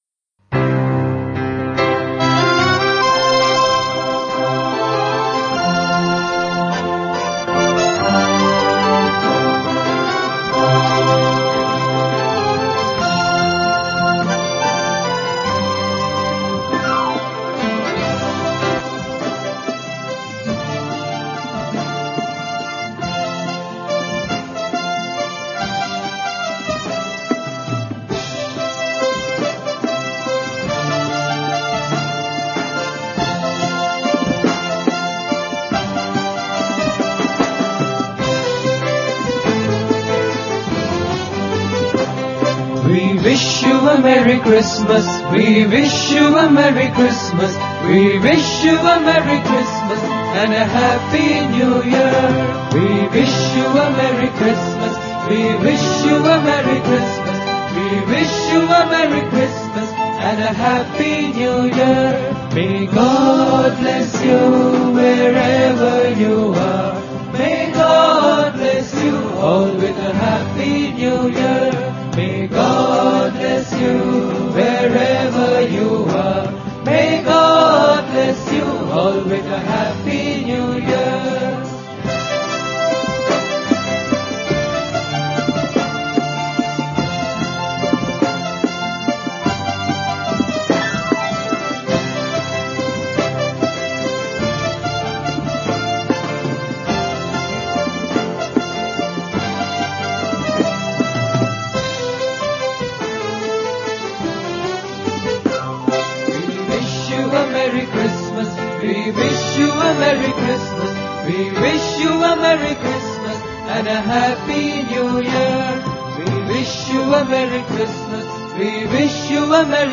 1. Devotional Songs
Major (Shankarabharanam / Bilawal)
6 Beat / Dadra
carols